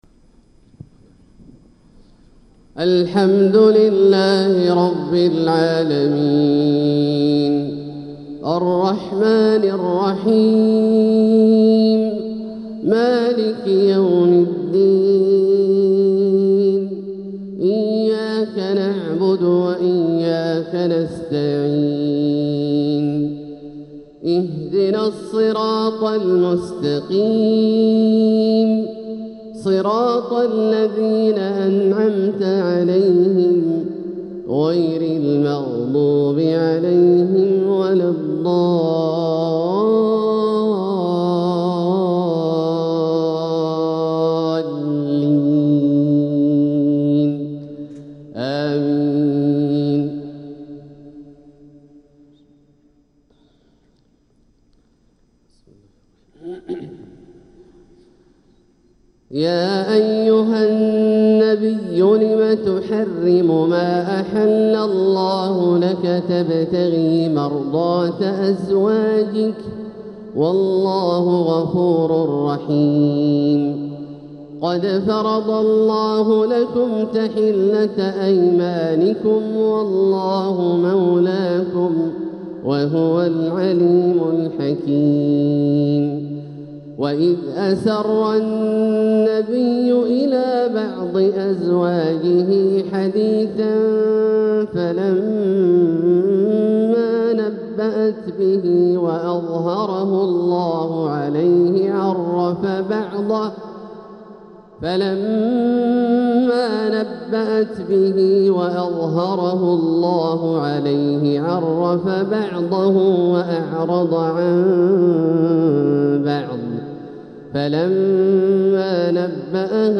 تلاوة لسورة التحريم كاملة | فجر الخميس 5 ربيع الأول 1447هـ > ١٤٤٧هـ > الفروض - تلاوات عبدالله الجهني